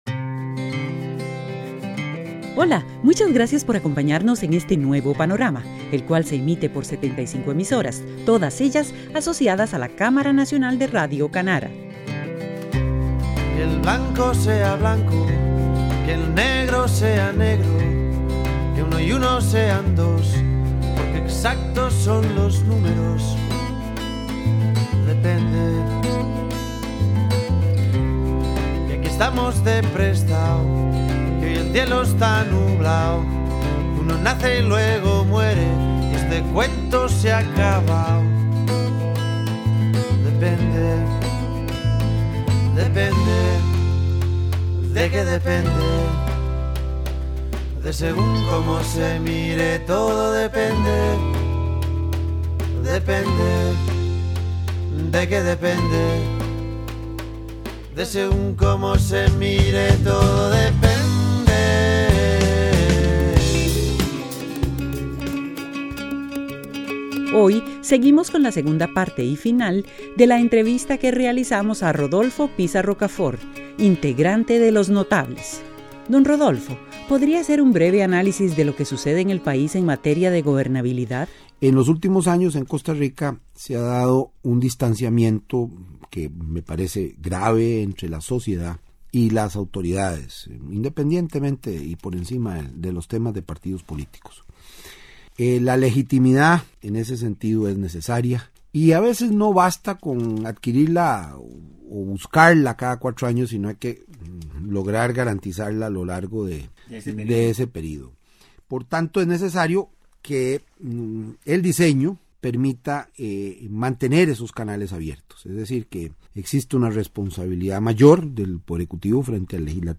Entrevista a Rodolfo Piza Rocaford 2